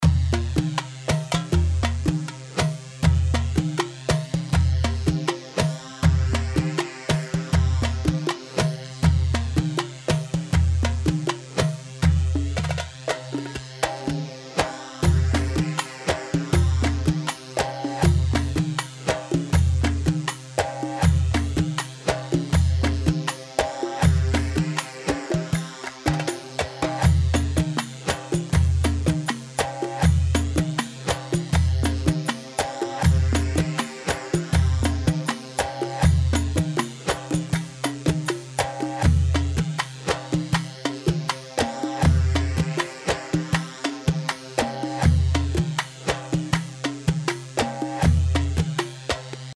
Hewa 4/4 82 هيوا